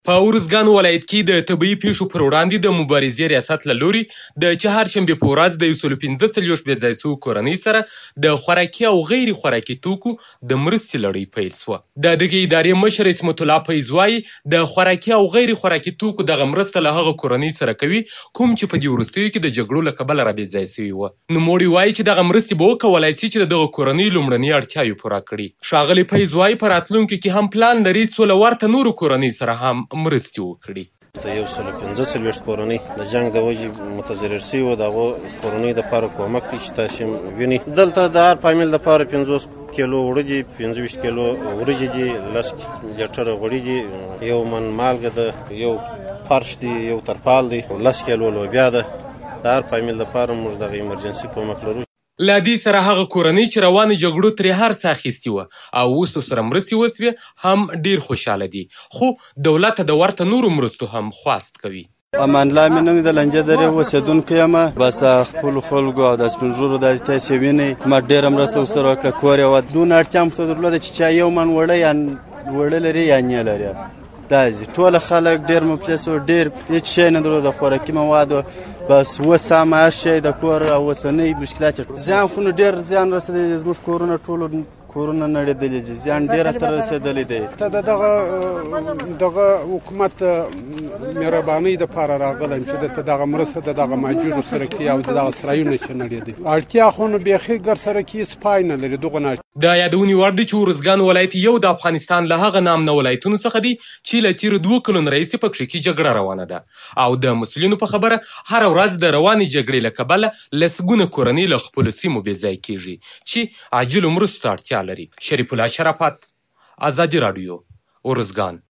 د ارزکان راپور